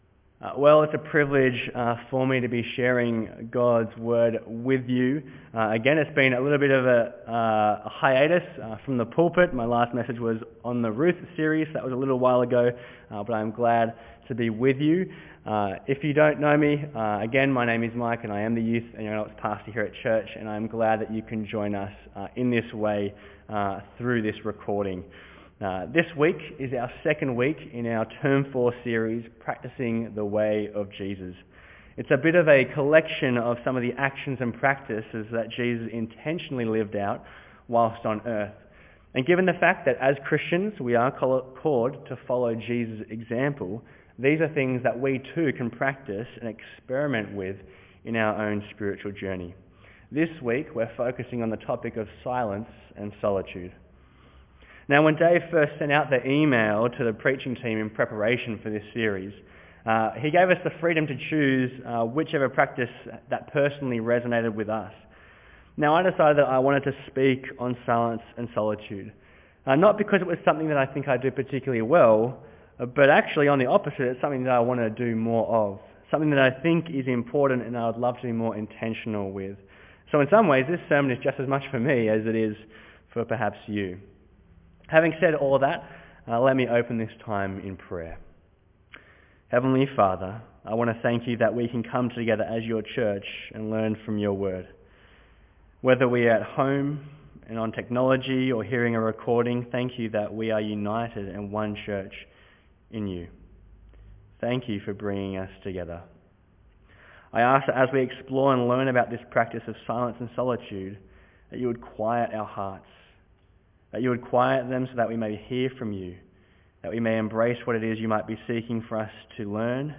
Bible Text: Mark 1:29-39; 1 Kings 19:9b-13 | Preacher